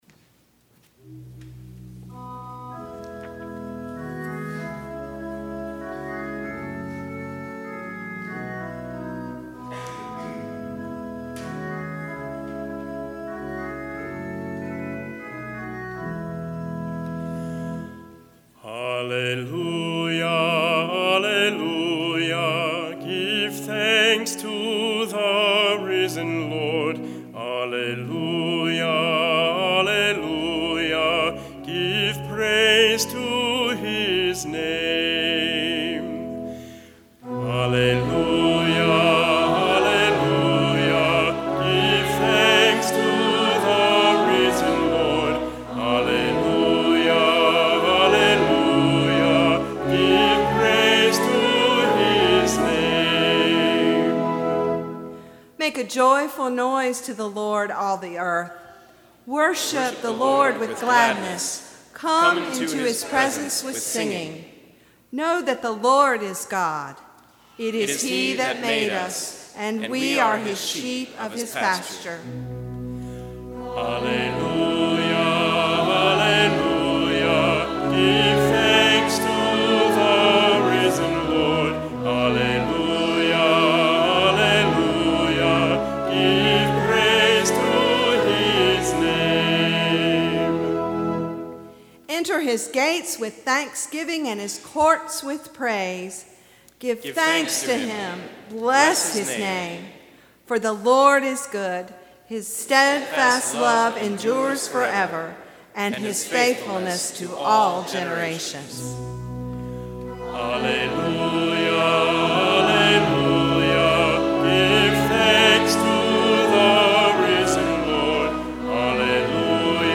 baritone